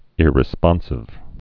(ĭrĭ-spŏnsĭv)